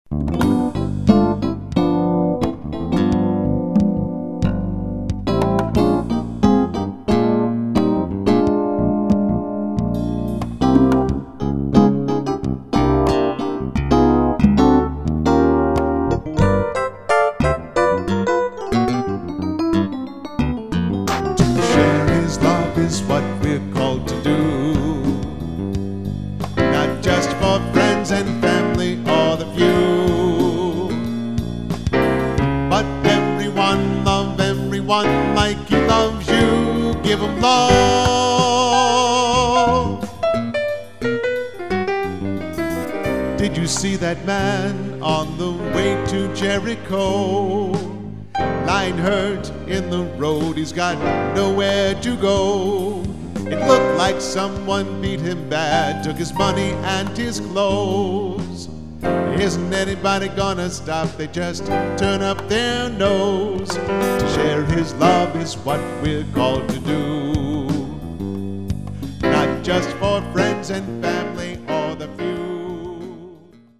Contemporary Christian music
Keyboard/Vocals